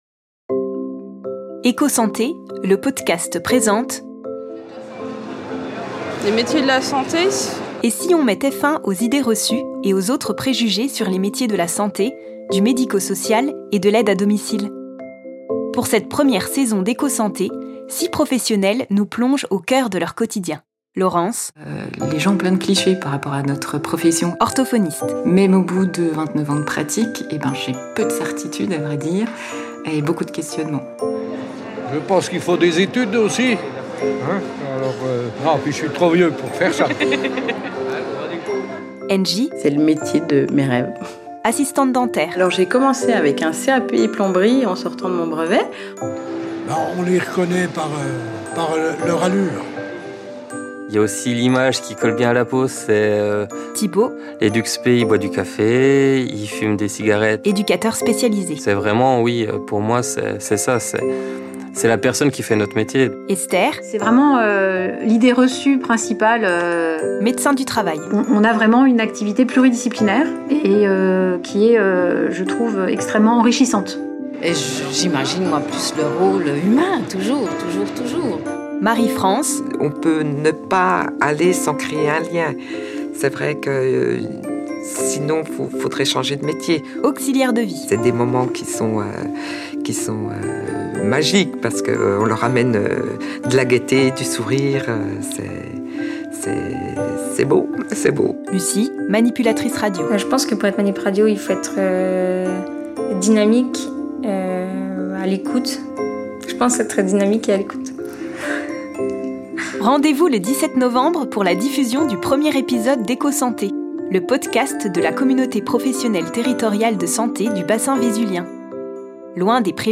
Extrait voix-Off - Teaser EchoSanté